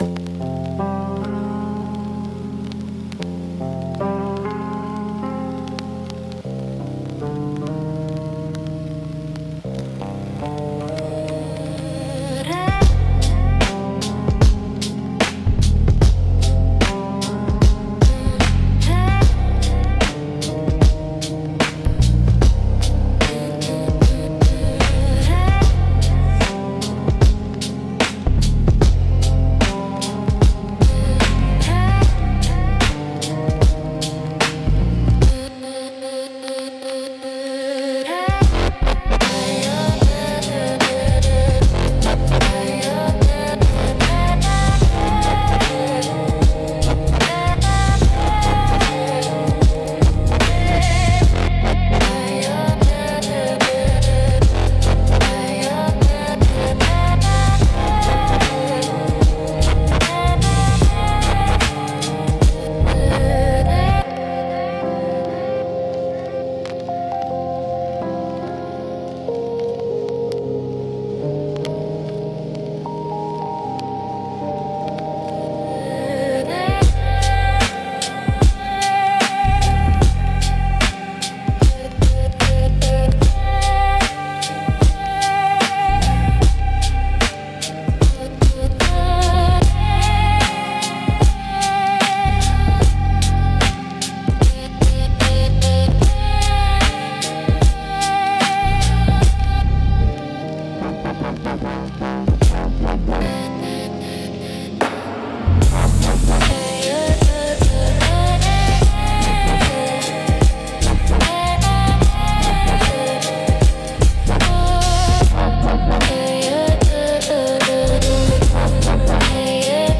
Instrumental - The Scratched Signal